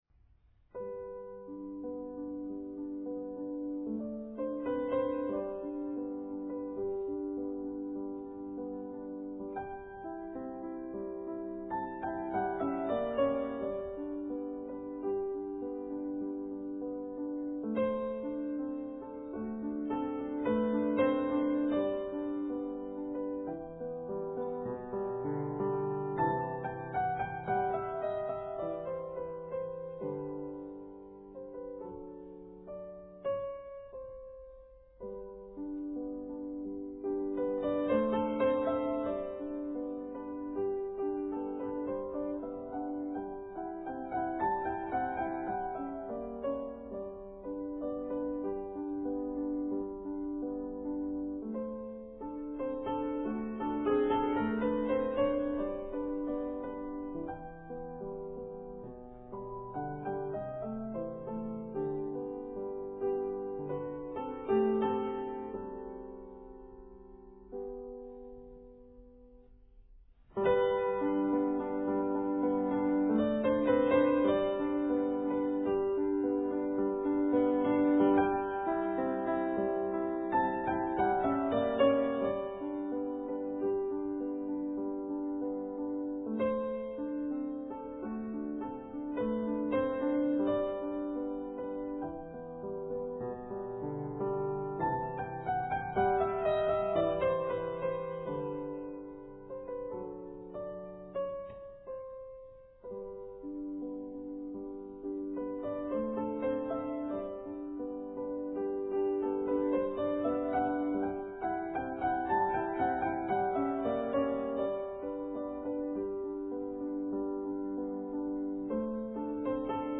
piano15.mp3